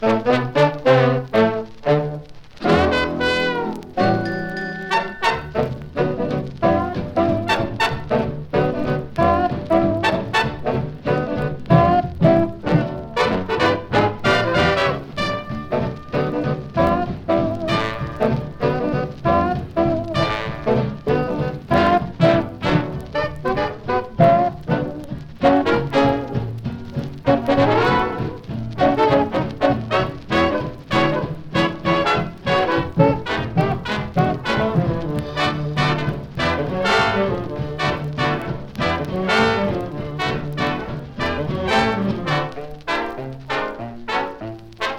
Jazz, Big Band, Swing　USA　12inchレコード　33rpm　Mono